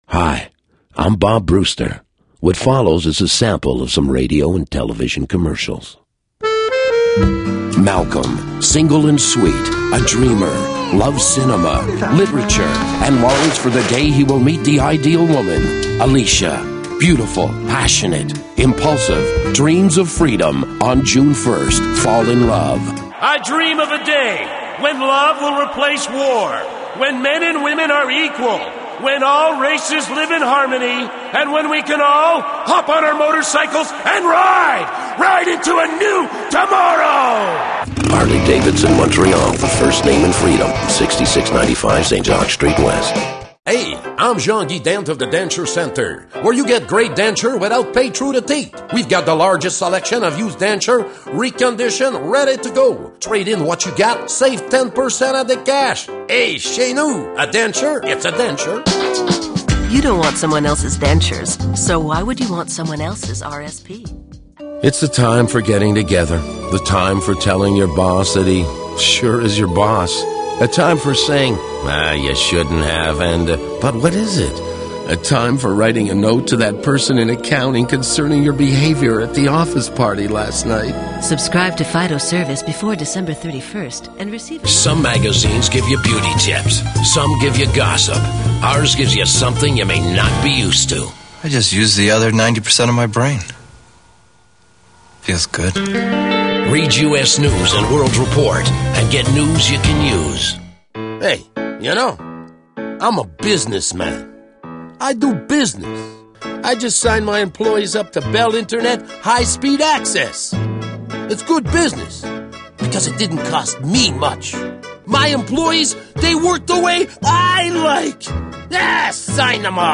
deep bass versatile funny
Sprechprobe: Werbung (Muttersprache):